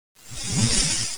00187_Sound_fly.mp3